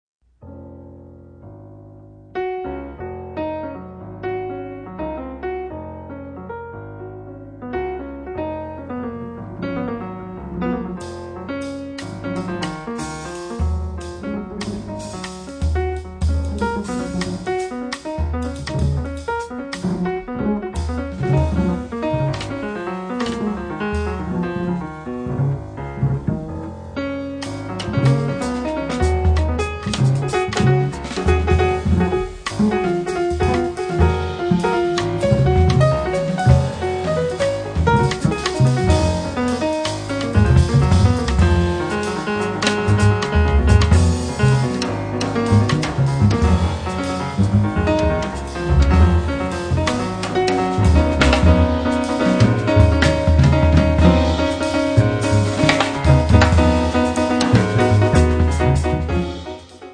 pianoforte
basso
batteria
pulsante, "fibonacciana", potrebbe non avere mai fine.